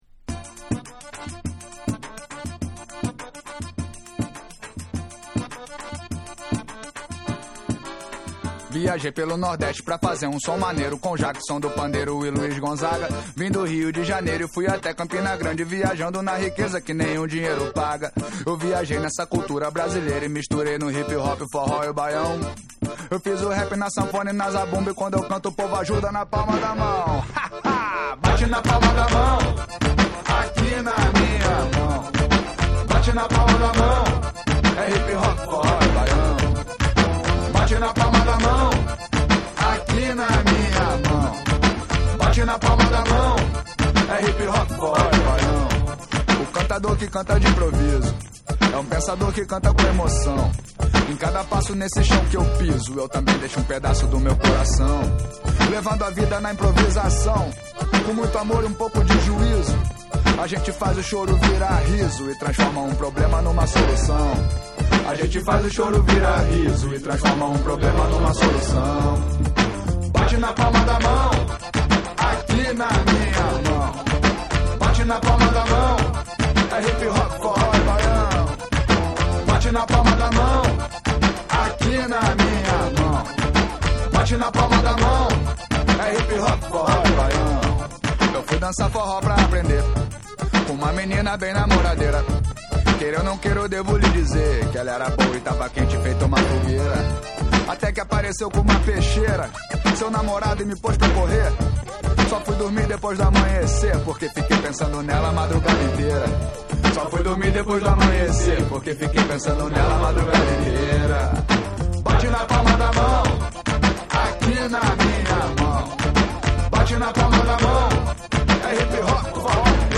WORLD / BRASIL